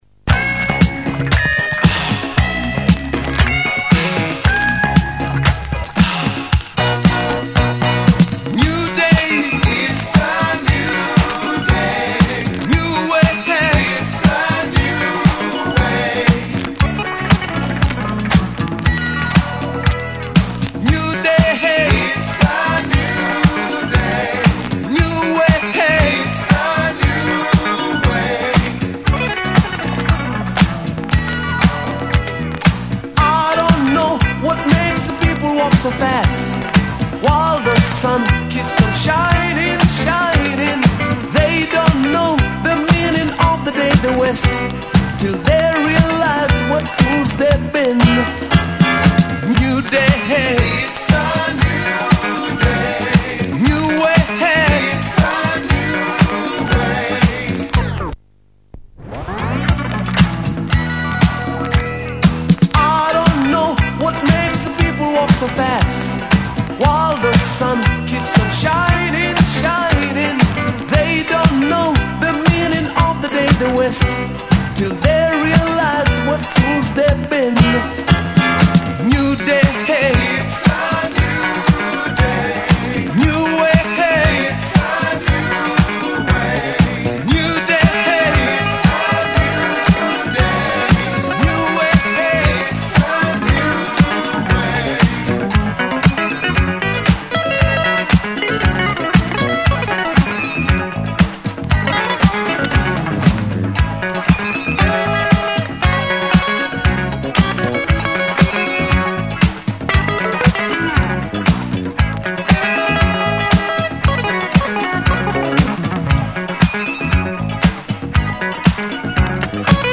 slightly reggae flavored disco effort on the A side.
is the highlight with it’s cosmic breakdown